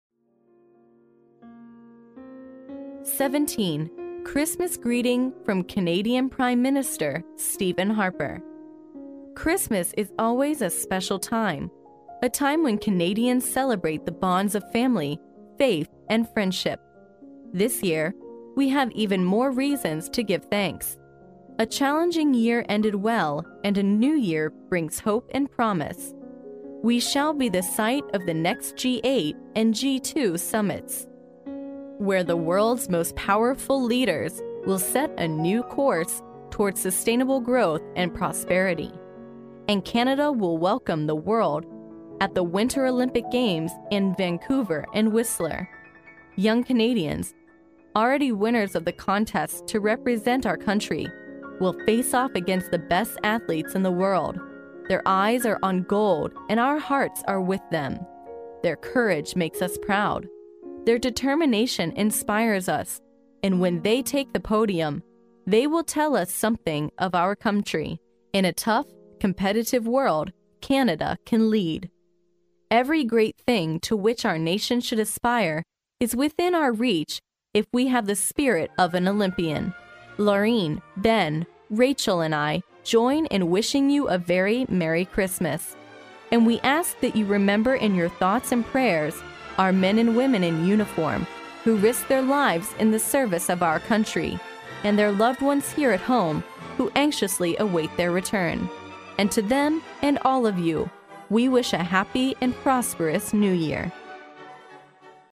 历史英雄名人演讲 第52期:加拿大总理史蒂芬.哈珀2009年圣诞致辞 听力文件下载—在线英语听力室